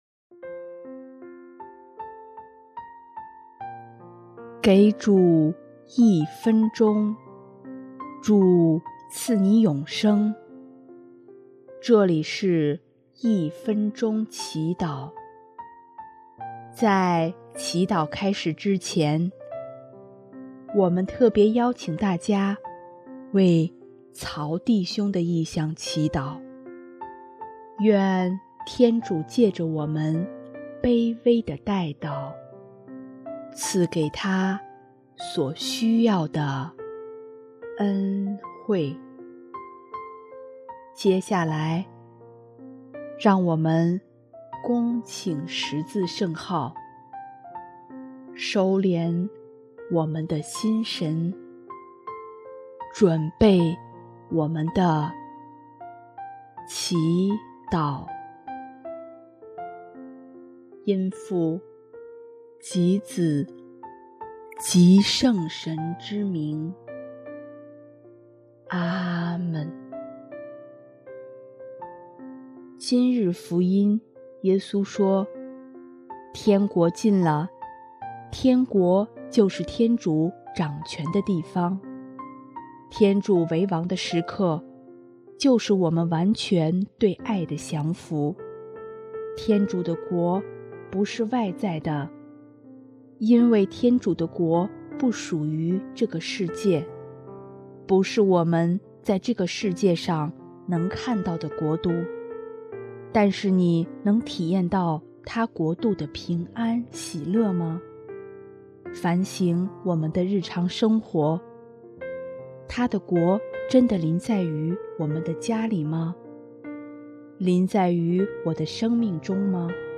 音乐： 主日赞歌《天国已临近》